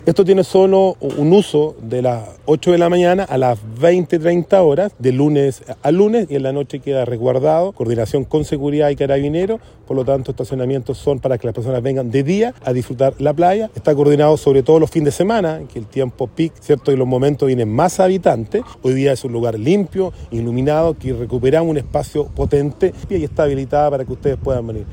El alcalde de la comuna de Penco, Rodrigo Vera, explicó cómo va a funcionar el estacionamiento que estará resguardado por personal municipal.
cuna-alcalde-penco.mp3